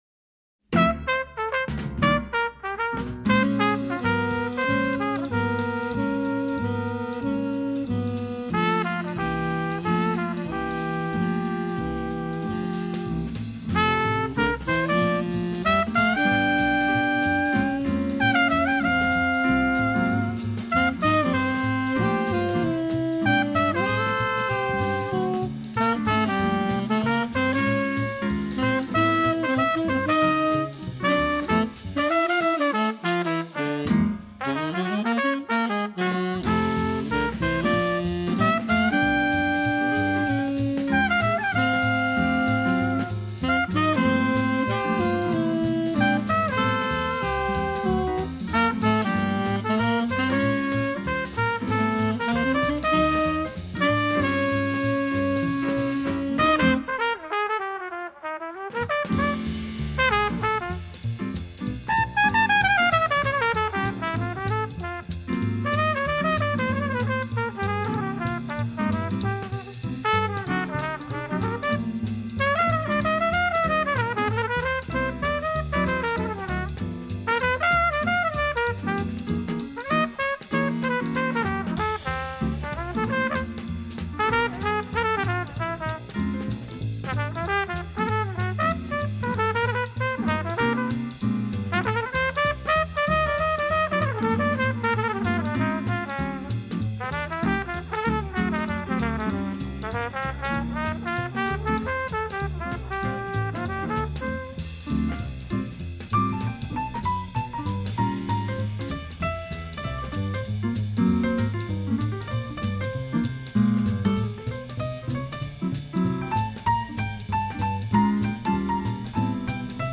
il est l’un des grands représentants du style Hard Bop
sax ténor
Trompette
piano
contrebasse
batterie